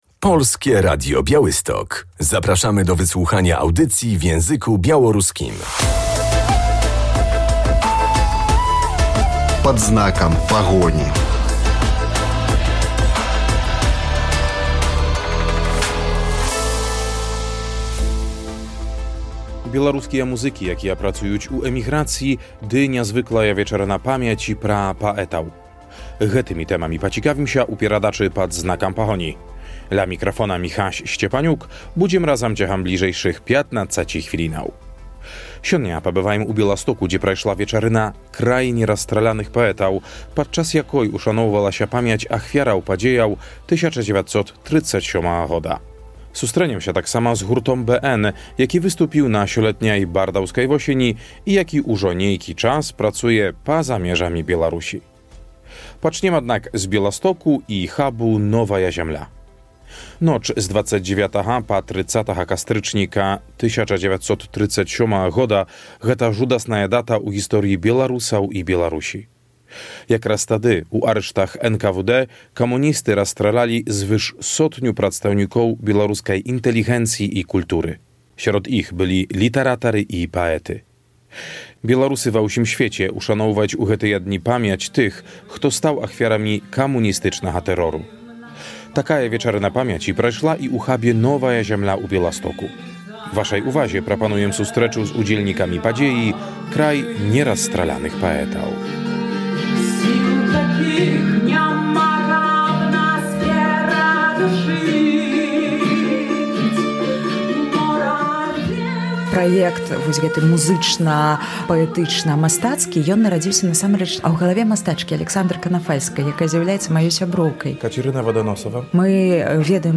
W audycji będziemy na koncercie Kraj (nie)rozstrzelanych poetów, który odbył się w Białymstoku.